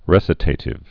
(rĕsĭ-tātĭv, rĭ-sītə-tĭv)